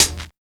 99 HAT.wav